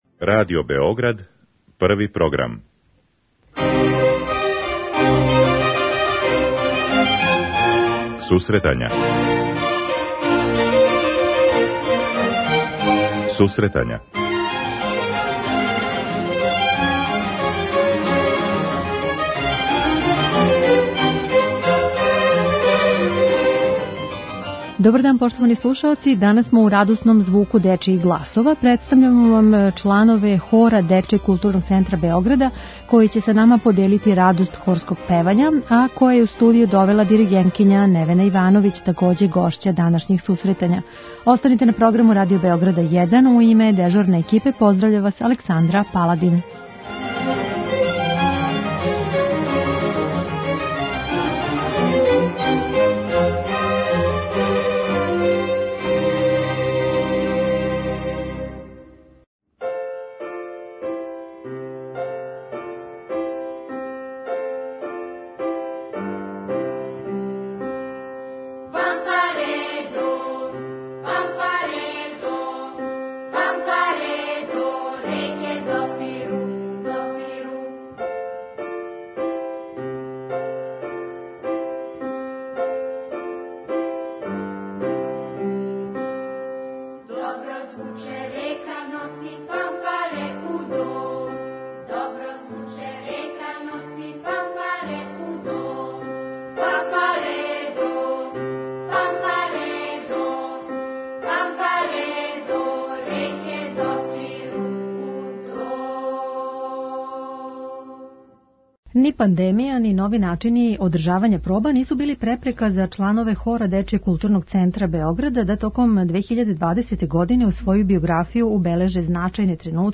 Данашња емисија ће бити у радосном звуку дечјих гласова.